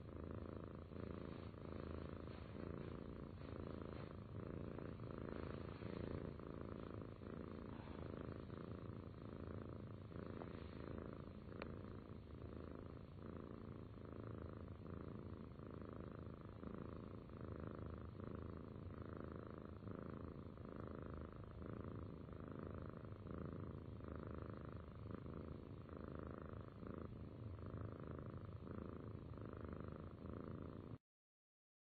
猫的声音" prrmeow
描述：猫叫声中夹杂着呼噜声。
标签： 猫声 MIAU miauw 呼噜声
声道立体声